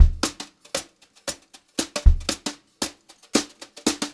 116JZBEAT4-L.wav